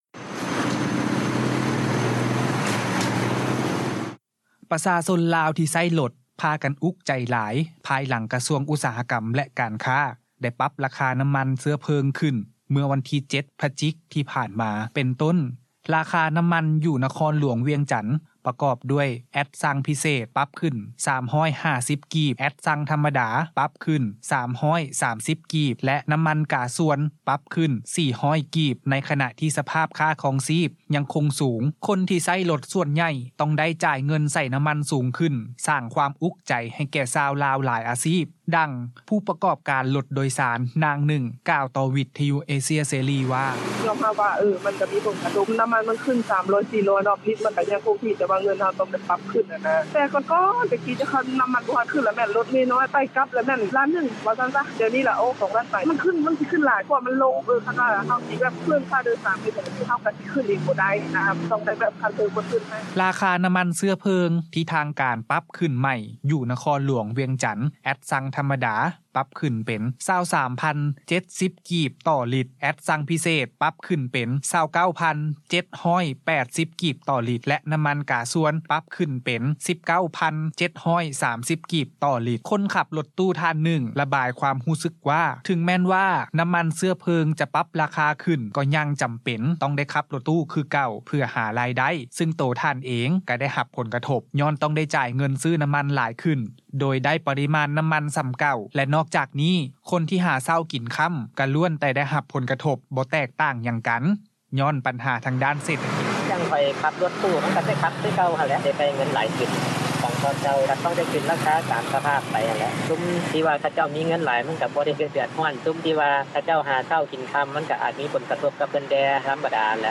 ໃນຂະນະທີ່ ສະພາບຄ່າຄອງຊີບຍັງຄົງສູງ ຄົນທີ່ໃຊ້ລົດສ່ວນໃຫຍ່ ຕ້ອງໄດ້ຈ່າຍເງິນໃສ່ນໍ້າມັນສູງຂຶ້ນ ສ້າງຄວາມອຸກໃຈ ໃຫ້ແກ່ຊາວລາວຫຼາຍອາຊີບ, ດັ່ງຜູ້ປະກອບການລົດໂດຍສານ ນາງໜຶ່ງ ກ່າວຕໍ່ວິທຍຸເອເຊັຽເສຣີ ວ່າ: